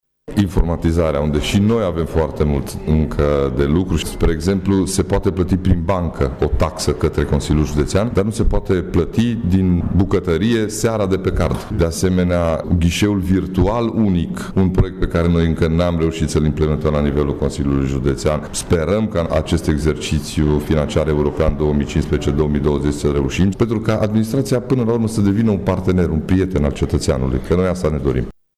Președintele CJ Mureș, Ciprian Dobre, este mulțumit de rezultatele raportului, dar recunoaște că mai sunt probleme legate de informatizare și de implementarea ghișeului unic: